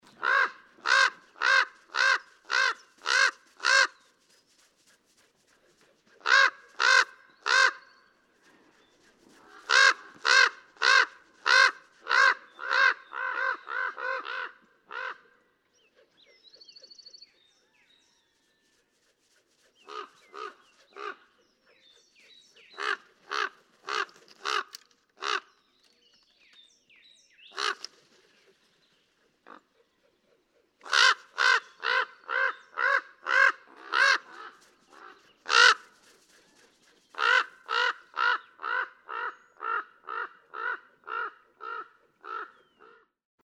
Common raven
Call matching, example 2.
223_Common_Raven.mp3